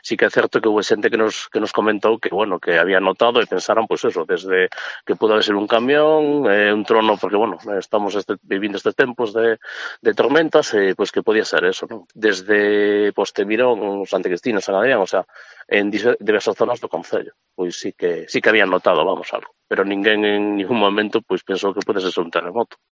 Declaraciones de César Poza, alcalde de Vilaboa